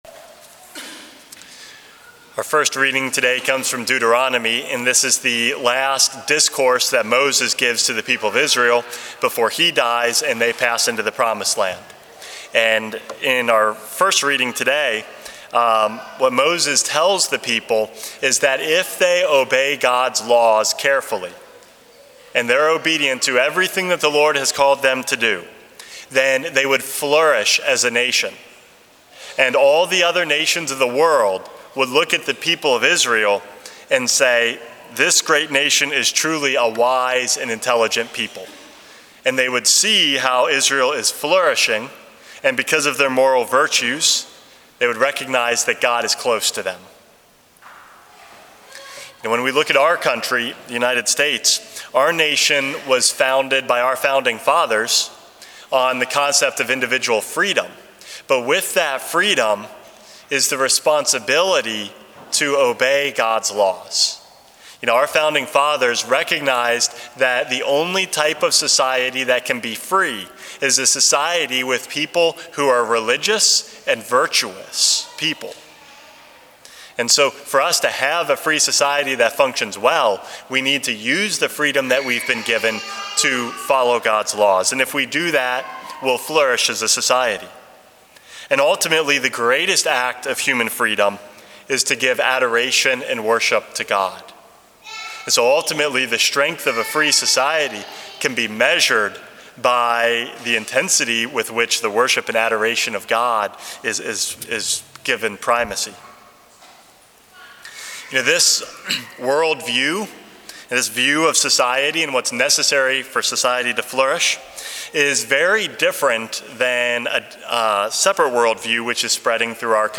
Homily #412 - A Wise and Intelligent Nation